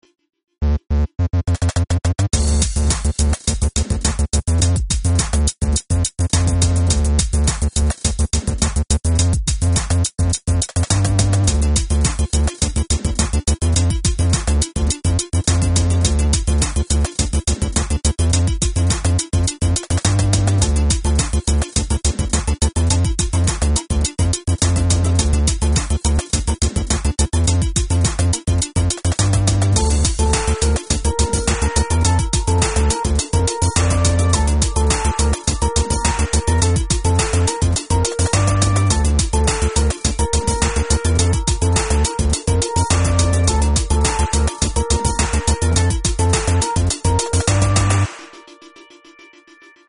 Злобненький отрывок трека.